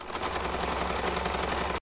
mechanic_sound.ogg